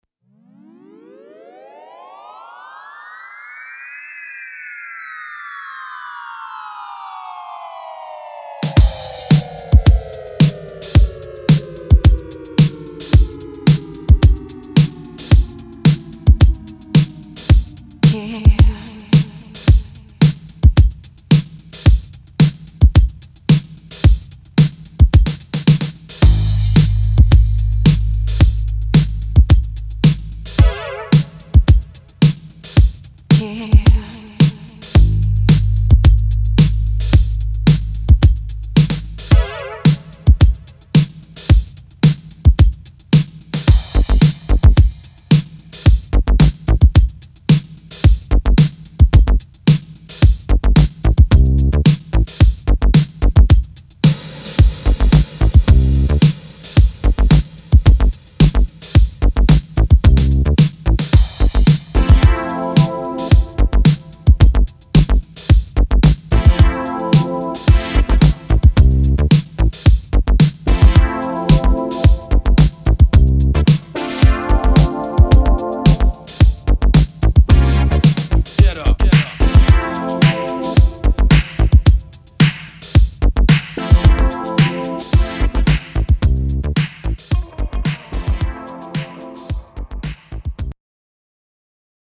'phat house flavour’
2 club bombs and a bonus downtempo-disco-boogie scorcher